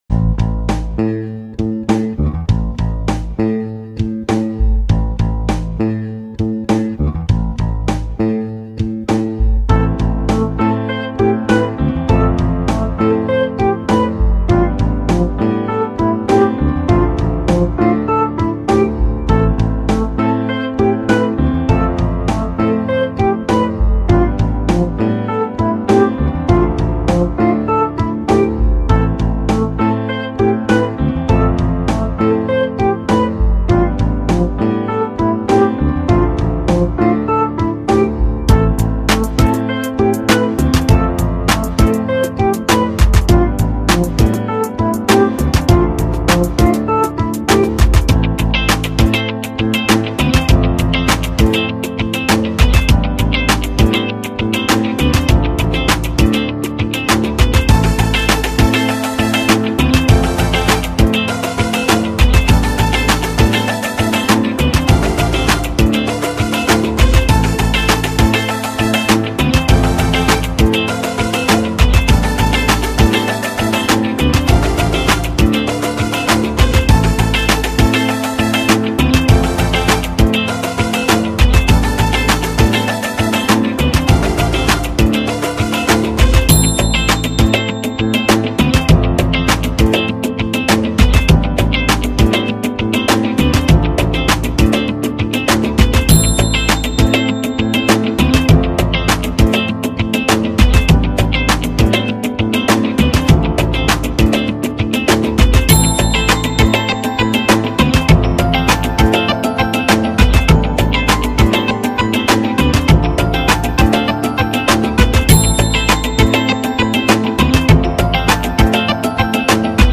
Relaxing